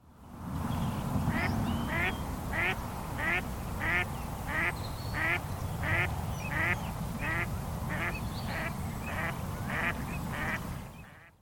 Sinisorsan äänet ovat sorsista tutuimpia, kuin
perusääniä, mutta niissä on runsaasti vivahteita, ja myös koiraan ja naaraan äänet eroavat sävyiltään.